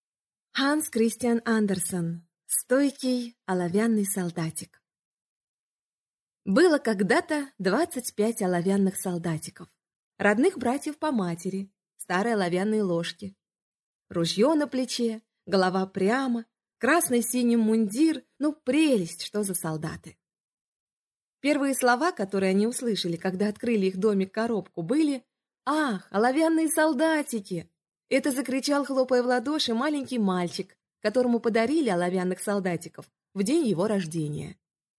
Аудиокнига Стойкий оловянный солдатик | Библиотека аудиокниг